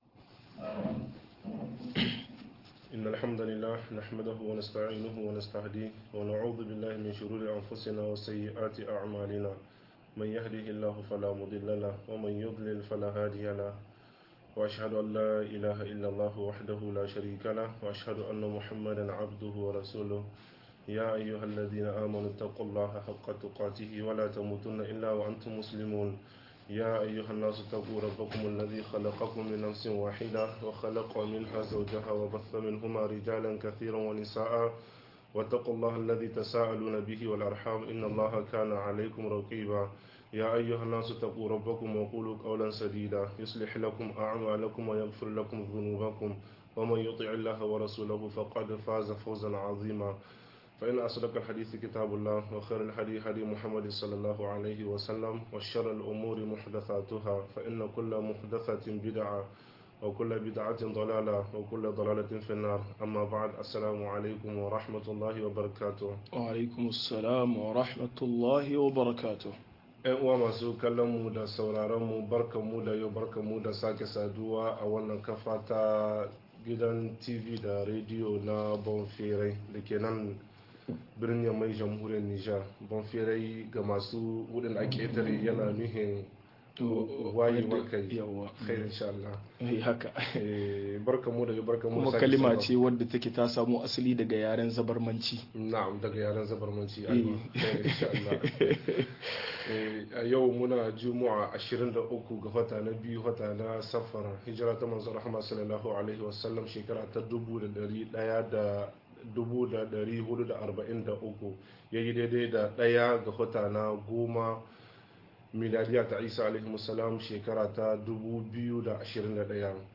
Hukuncin Bara a Muslunci - MUHADARA